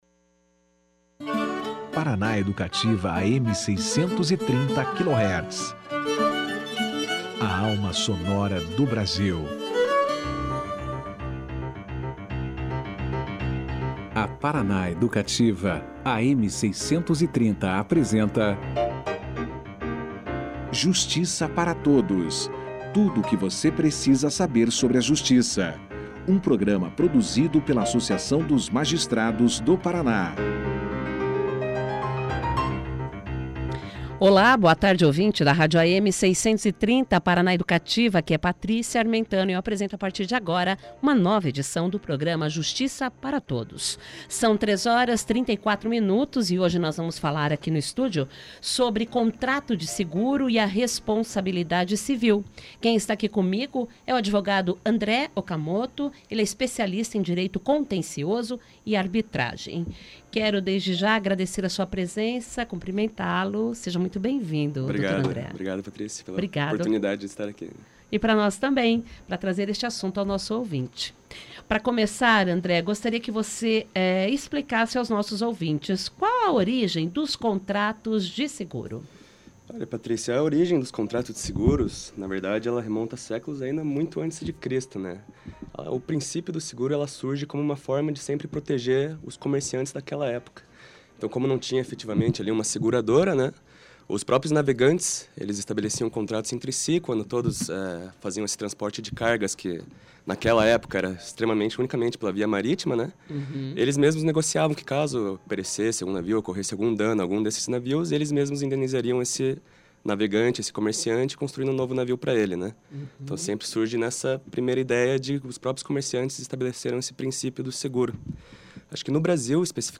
Confira aqui a aentrevista na íntegra.